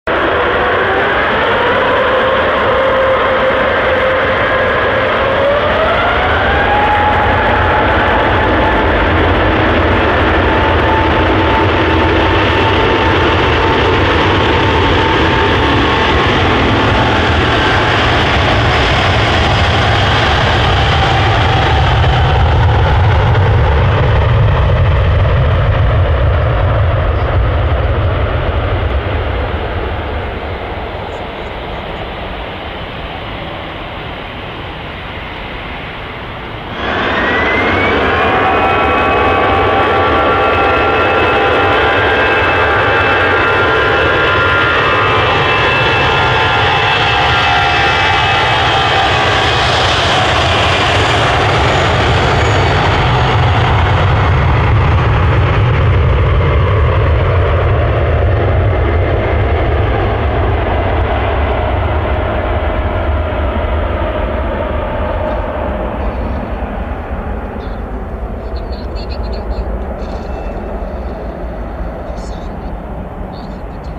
Two LOUD Departures! 🤩🔊 sound effects free download